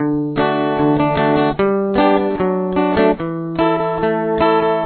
Verse
This is a G, C, G/B, D chord progression.